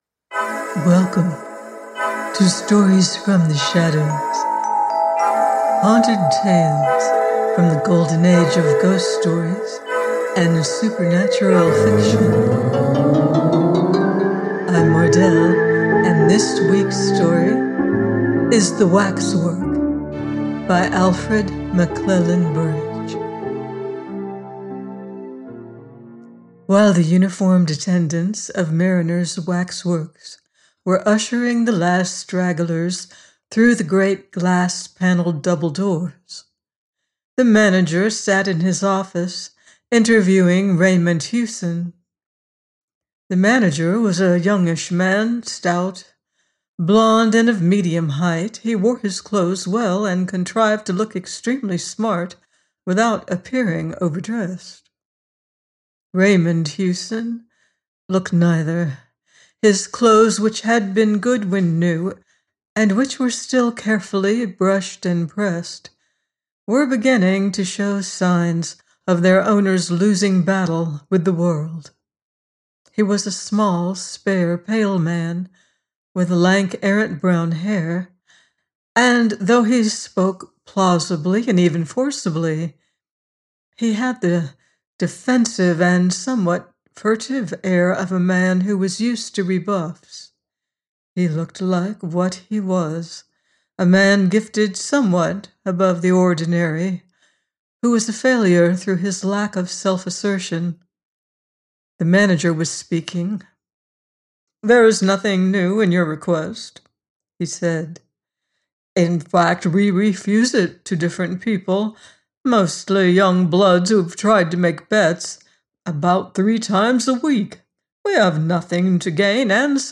The Waxwork – Alfred Burrage - audiobook